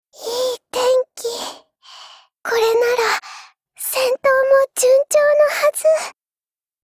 Cv-30104_battlewarcry.mp3